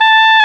Index of /90_sSampleCDs/Roland LCDP04 Orchestral Winds/WND_Oboe 9-13/WND_Oboe Short
WND OBOE-F.wav